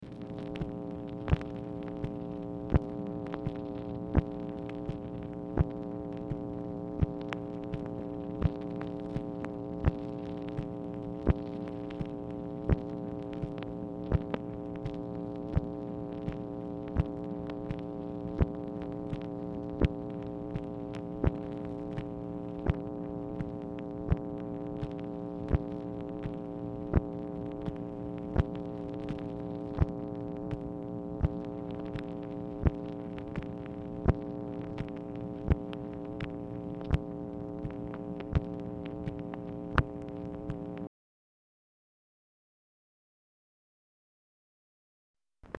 Telephone conversation # 7638, sound recording, MACHINE NOISE, 5/12/1965, time unknown | Discover LBJ
Format Dictation belt
Specific Item Type Telephone conversation